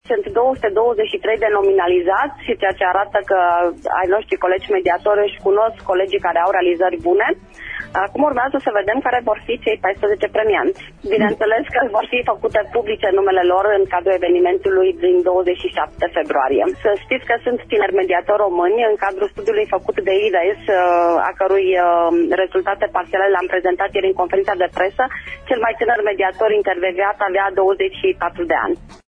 invitată azi în emisiunea „Pulsul Zilei”: